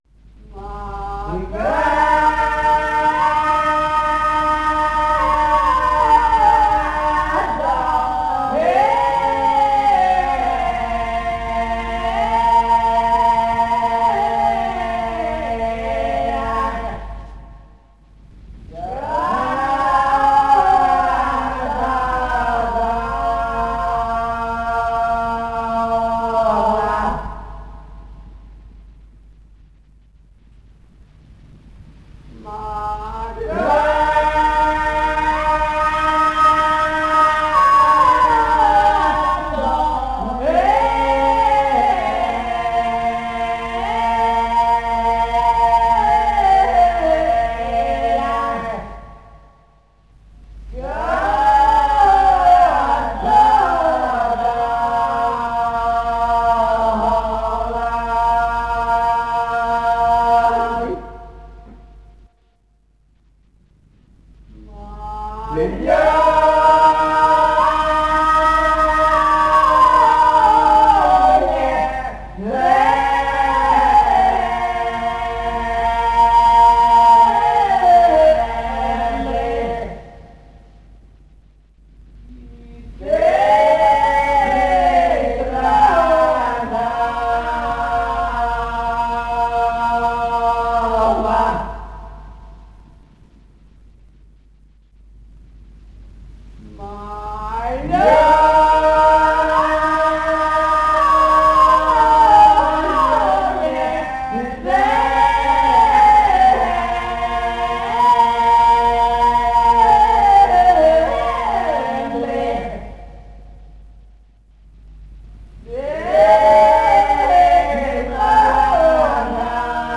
Recupero folklore Musicale e Tradizioni nell'area italo albanese